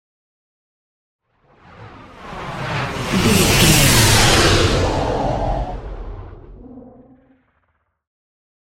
Whoosh large heavy
Sound Effects
dark
intense